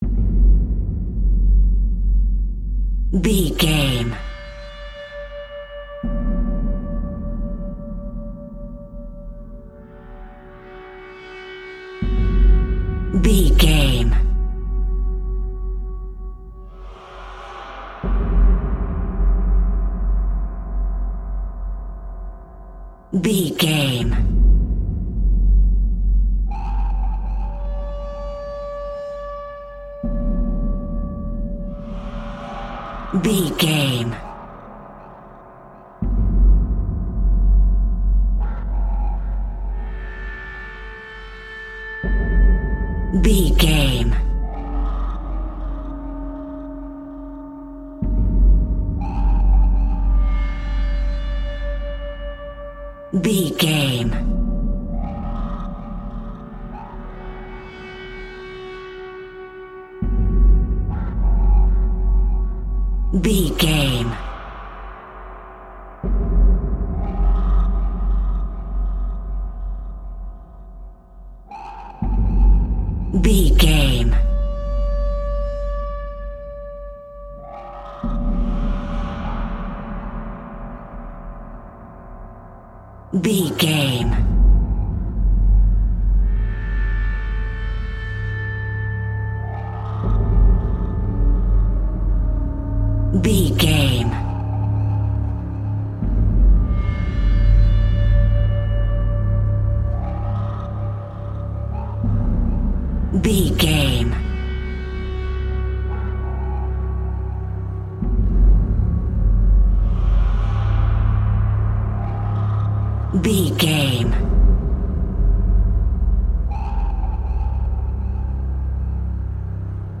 In-crescendo
Atonal
Slow
scary
ominous
eerie
horror
instrumentals
Horror Pads
Horror Synths